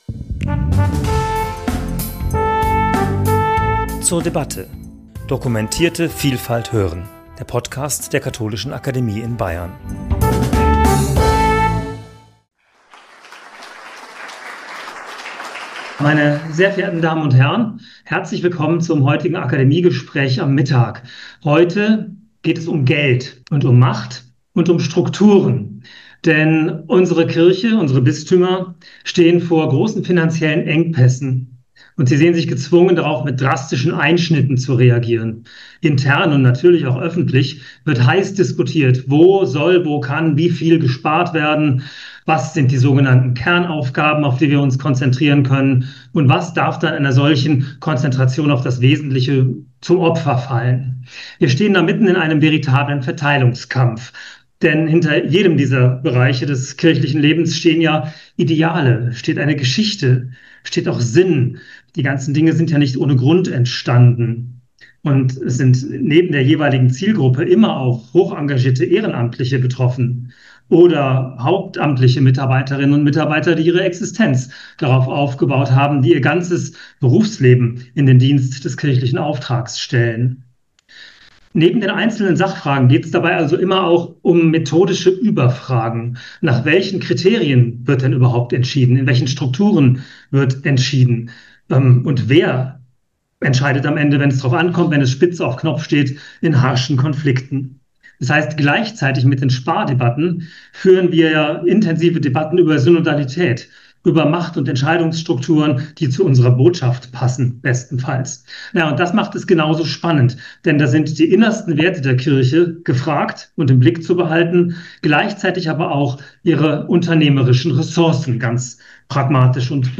Gespräch zum Thema 'Geld, Strukturen, Macht: das „Unternehmen Kirche“ im Umbau' ~ zur debatte Podcast